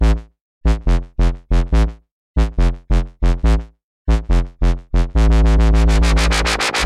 描述：记录在TASCAM ZOOM H4n上，XY胶囊设置为90°。 STEREO透视STEREO录音。 跳棋的氛围声。人们在说话。购物车。
标签： 运动 商店 公共 颤振 OWI 氛围 喃喃自语 摇铃 精力充沛 跳棋 购物
声道立体声